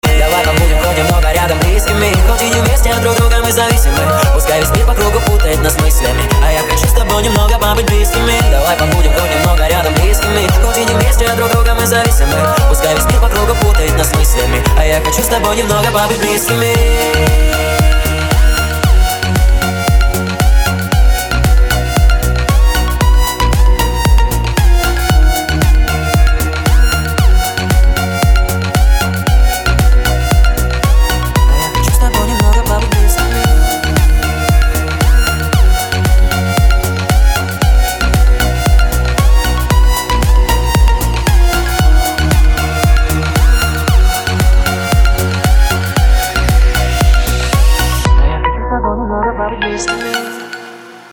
• Качество: 320, Stereo
мужской вокал
громкие
dance
Club House
электронная музыка
клубняк